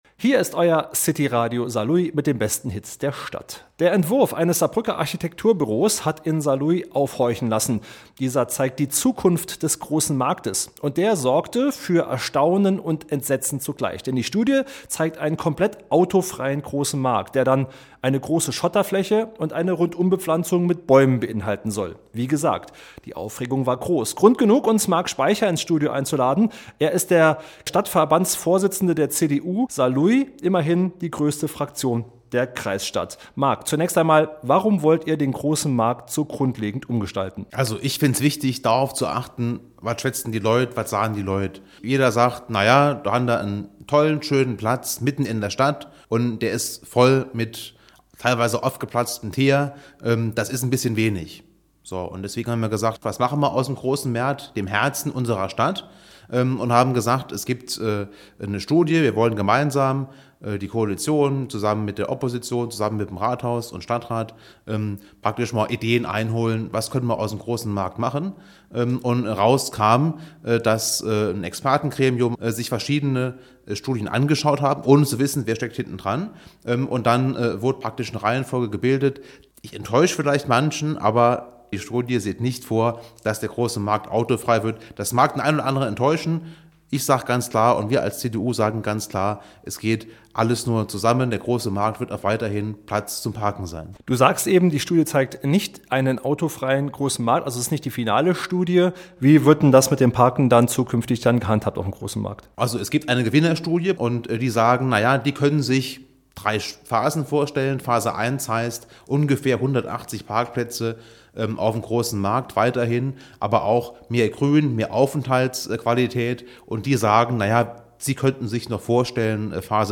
Interview mit Saarlouis CDU-Chef Marc Speicher, Teil 1: